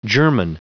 Prononciation du mot germen en anglais (fichier audio)
Prononciation du mot : germen